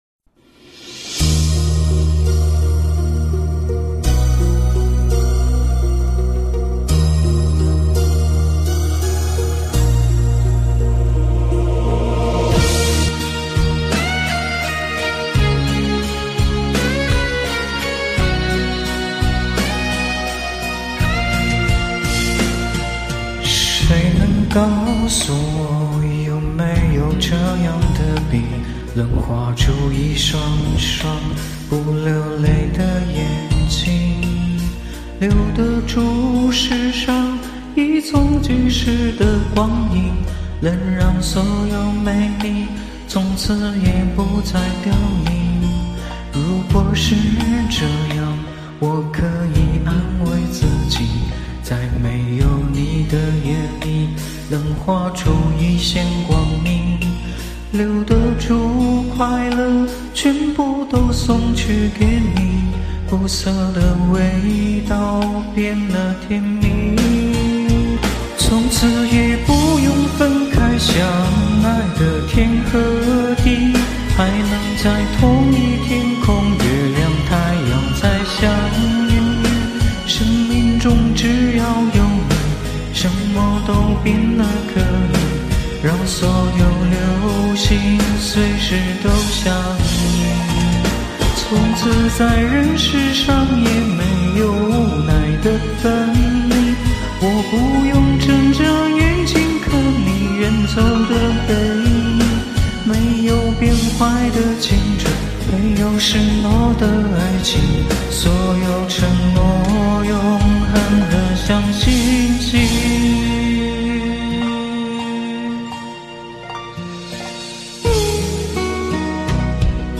前奏的钢琴如教堂彩窗漏下的光，静谧中带着天顶的回声。选手一开口便摒弃了所有滑音装饰，像把宝剑收入绸囊，只余沉稳的呼吸感。